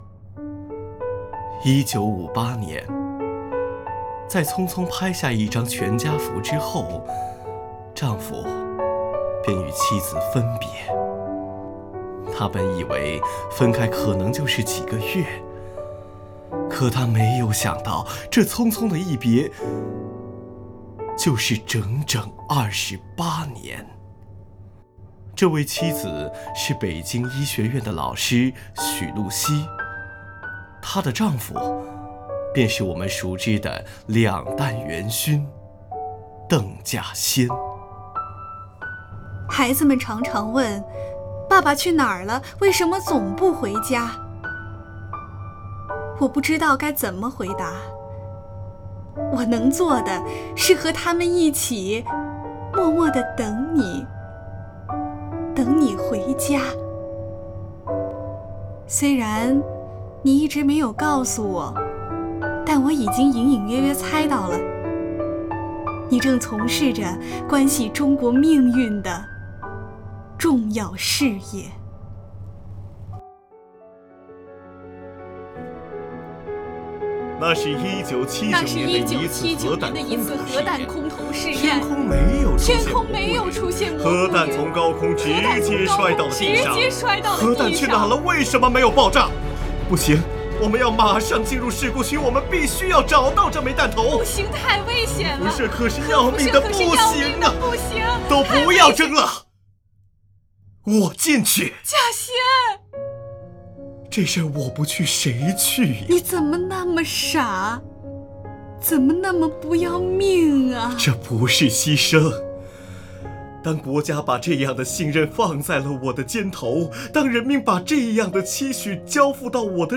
作品情感真挚，既有家国使命的伟大，也有个人牺牲的深沉，用朗诵的方式向那些为祖国默默奉献的科学家们以及他们背后的亲人致敬。